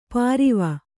♪ pāriva